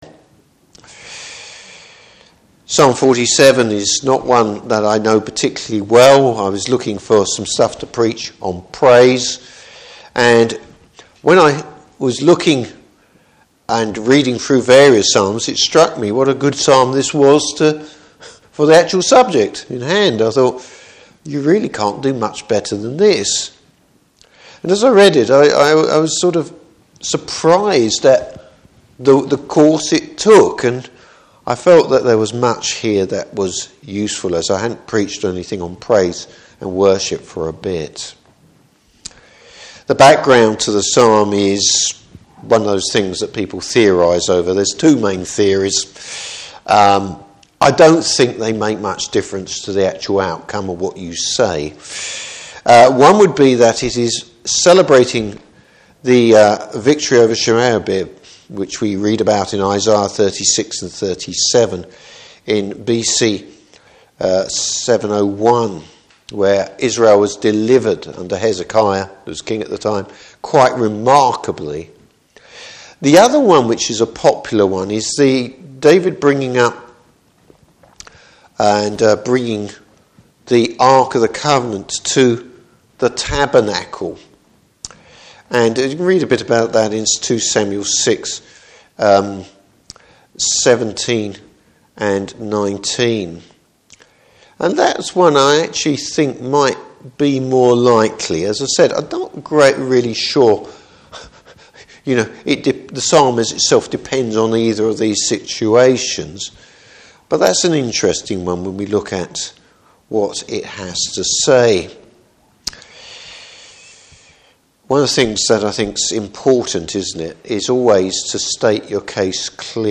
Service Type: Evening Service The command to praise the Lord. t Topics: Praise « Christian Generosity, its Motivation.